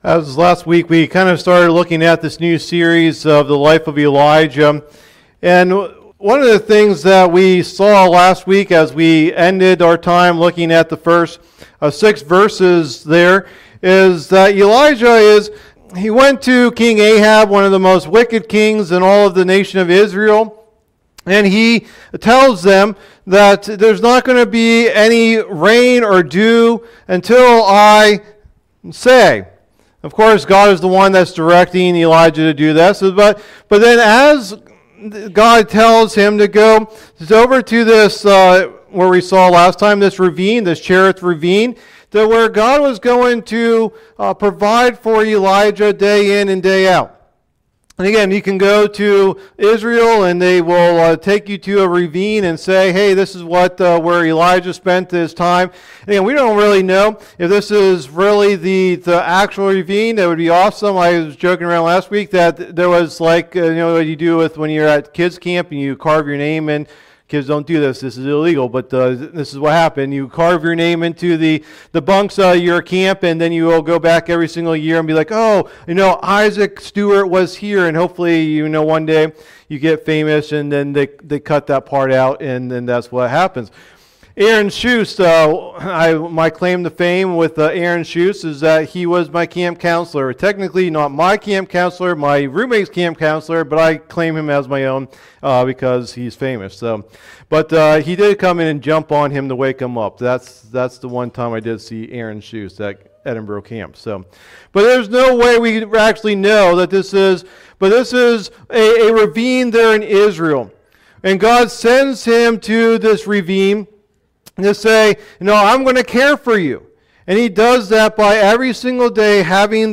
Message #3 in the "Life of Elijah" teaching series